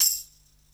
Perc (9).wav